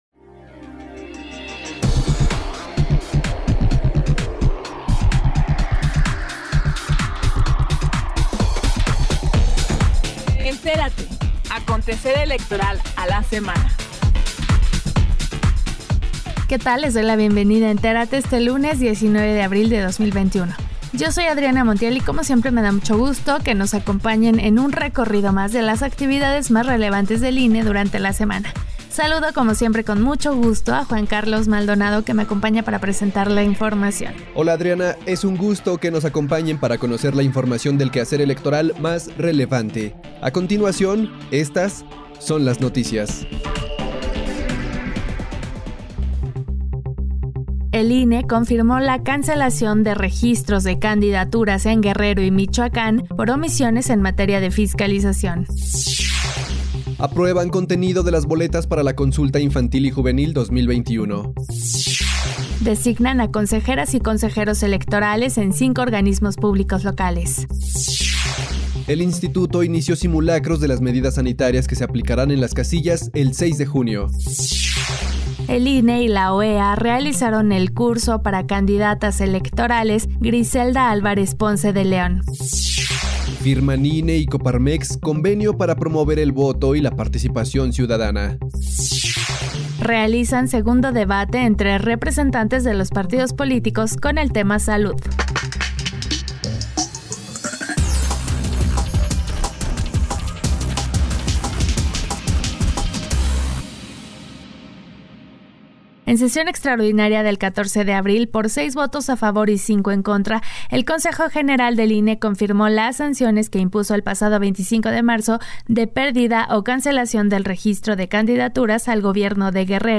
NOTICIARIO 19 DE ABRIL 2021